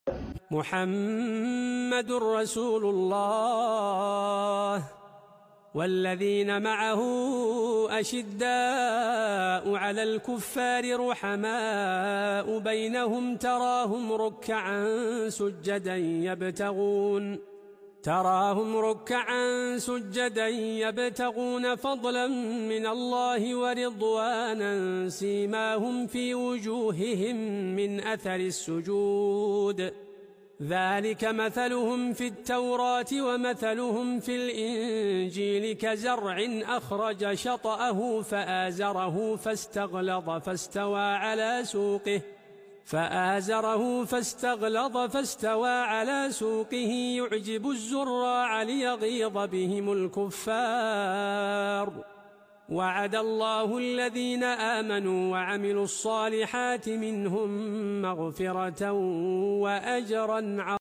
✨ La Lecture Du Coran Sound Effects Free Download